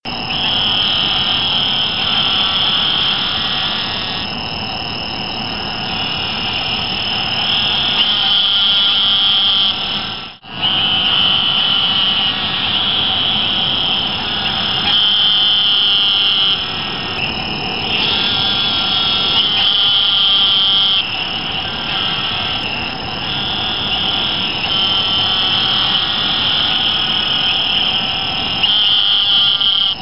Here's the faint, bleating call of Gastrophryne carolinensis, with the recording device held close to them! These are not "loud", and may be a good one to use if you're in an environment where a less-startling tone is preferred!